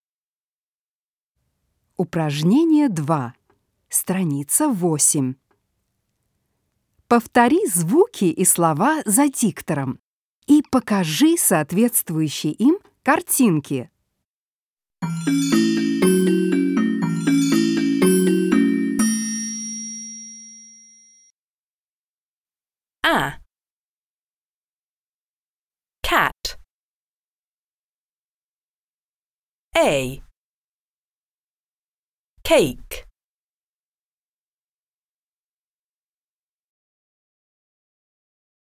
2. Повтори звуки и слова за диктором и покажи соответствующие им картинки.